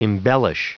Prononciation du mot embellish en anglais (fichier audio)
Prononciation du mot : embellish